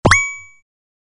成功音.mp3